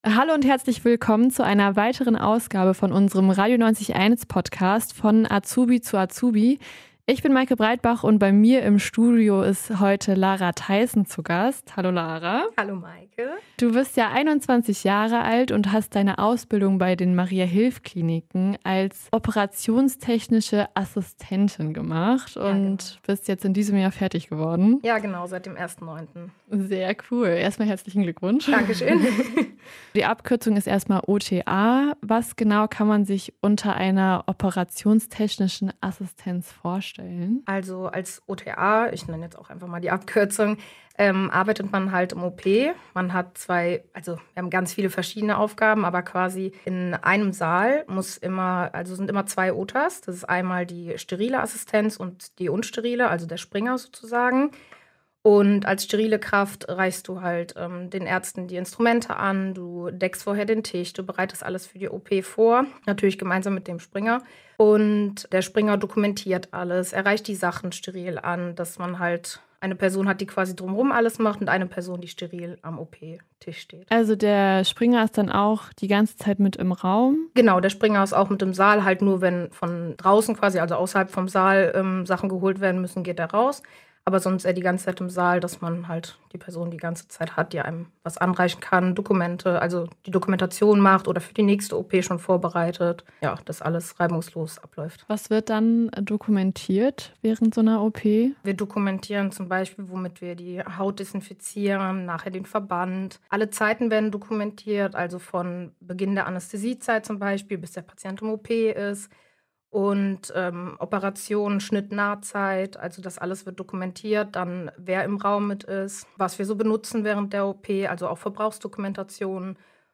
Das fragen junge Mitarbeiter von Radio 90,1 die Auszubildenen der Maria-Hilf-Kliniken.